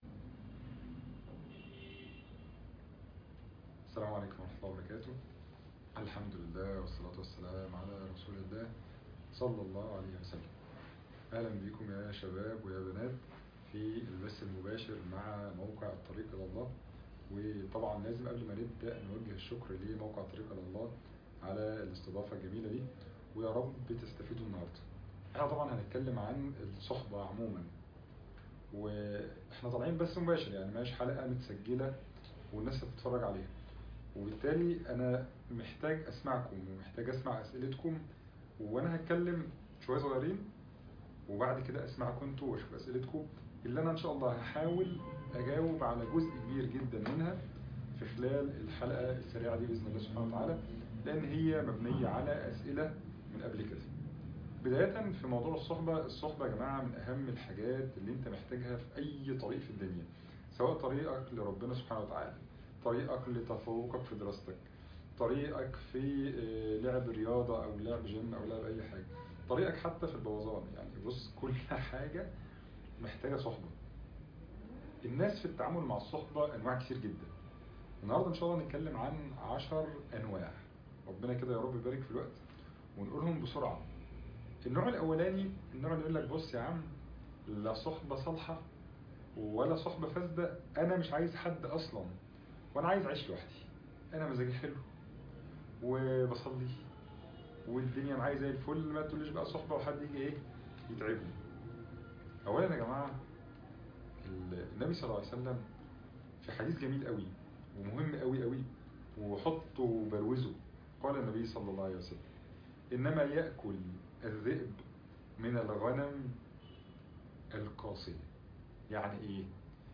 لقاء ..صحبة صالحة